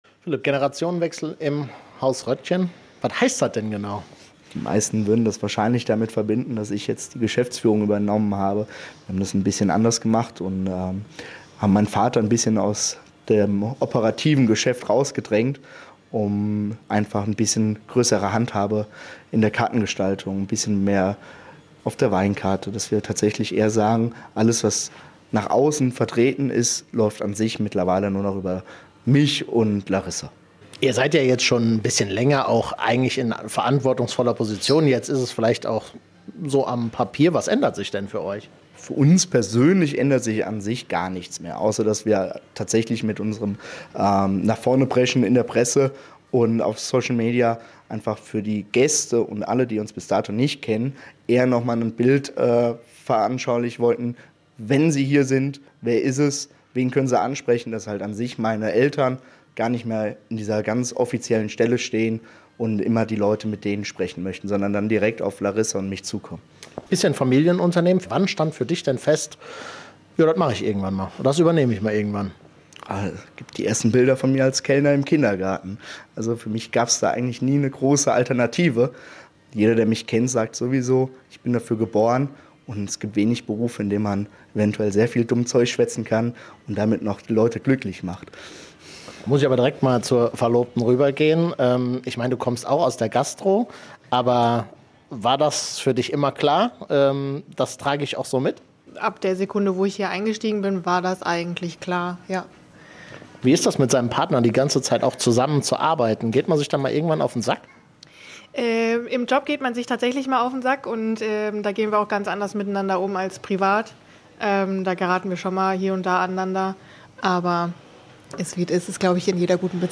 Neue Generation übernimmt Hotel Haus Rödgen - XXL Interview
xxl-interview-hotel-haus-roedgen.mp3